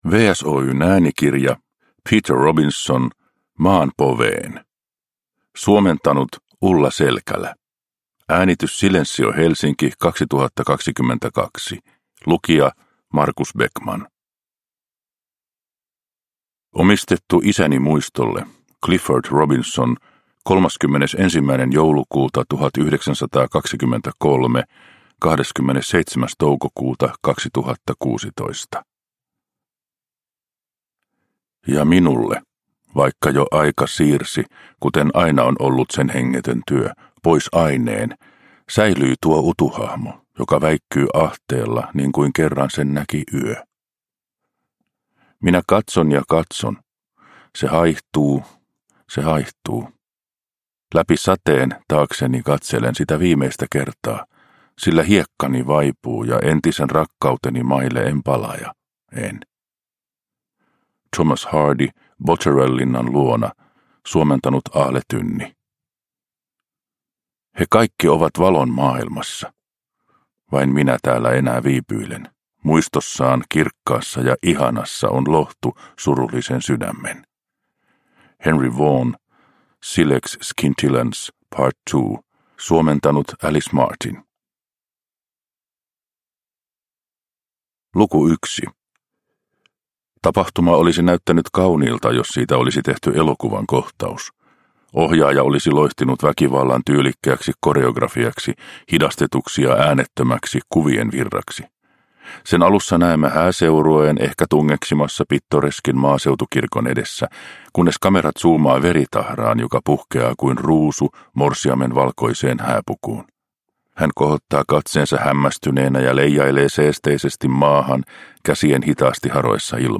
Maan poveen – Ljudbok – Laddas ner